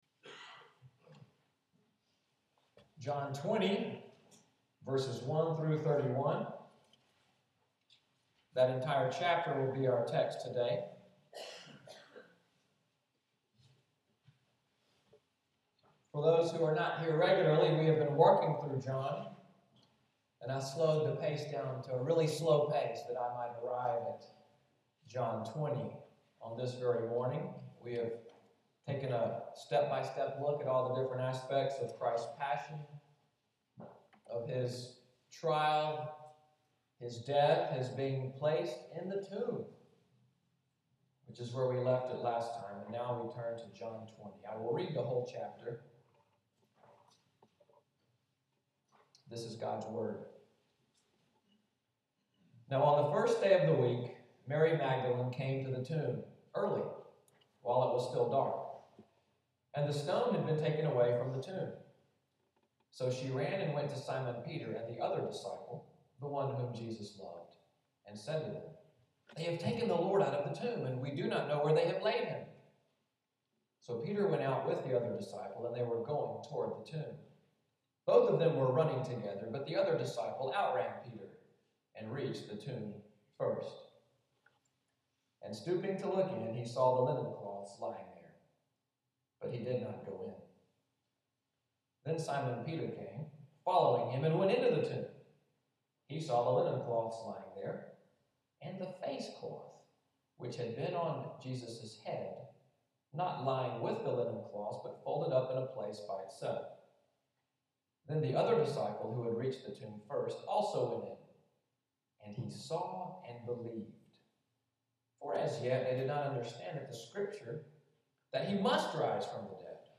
Sermon outline and Order of Worship